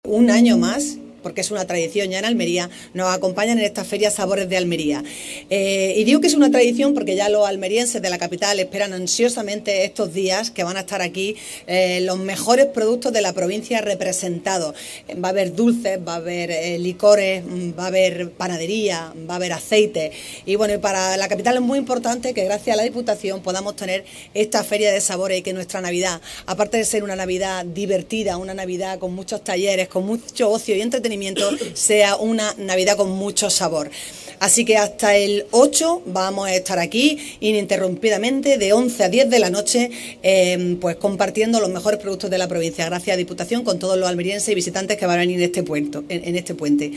Esta mañana, el presidente de la Diputación de Almería, José Antonio García Alcaina,          y la alcaldesa de Almería, María del Mar Vázquez, acompañados por el diputado de    Presidencia y Promoción de la Provincia, Carlos Sánchez, y la concejala de Comercio     del Ayuntamiento de Almería y diputada provincial, Lorena Nieto, junto a otras         autoridades, han inaugurado la Feria Sabores Almería.
ALCALDESA-SABORES-ALMERIA-NAVIDAD.mp3